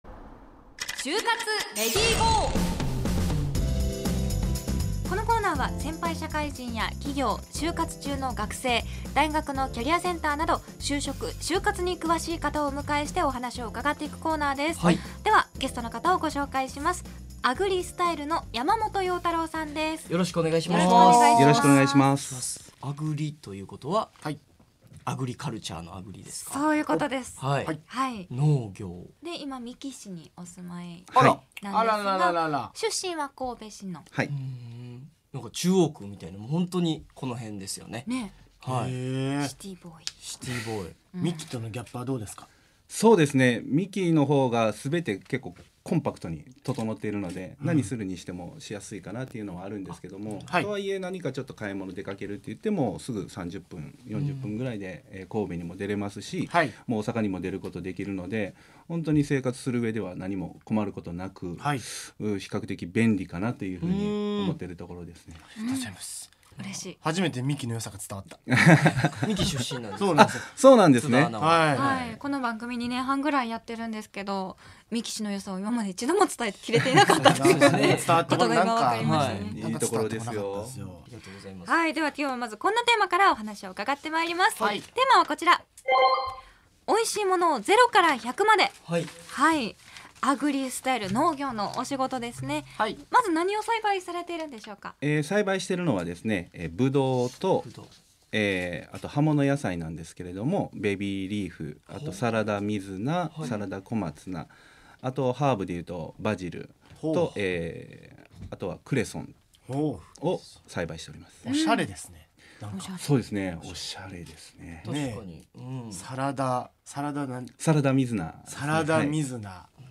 就活トークを展開した。